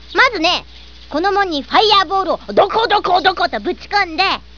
Lina explains her Fireball idea, complete with sound effects(4.58 sec, 50K)